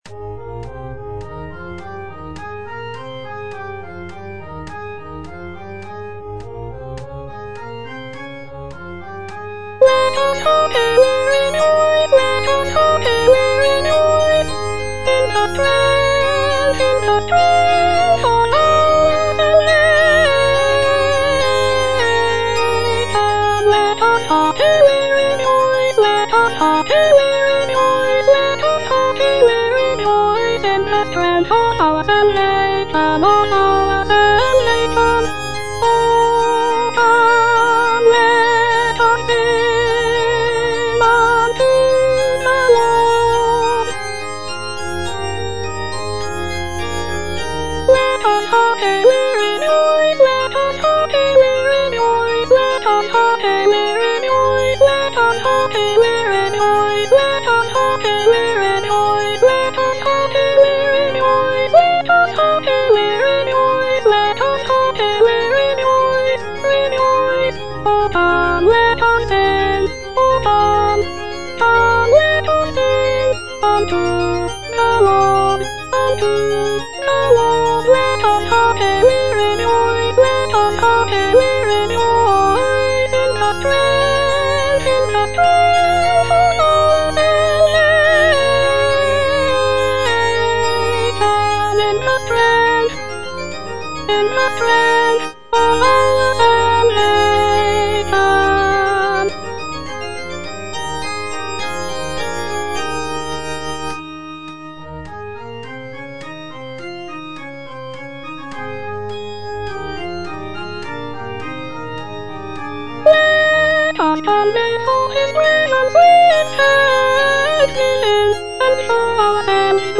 Choralplayer playing O come, let us sing unto the Lord - Chandos anthem no. 8 HWV253 (A = 415 Hz) by G.F. Händel based on the edition CPDL #09622
G.F. HÄNDEL - O COME, LET US SING UNTO THE LORD - CHANDOS ANTHEM NO.8 HWV253 (A = 415 Hz) O come, let us sing unto the Lord - Soprano (Voice with metronome) Ads stop: auto-stop
It is a joyful and celebratory piece, with uplifting melodies and intricate harmonies. The work is known for its grandeur and powerful expression of praise and worship.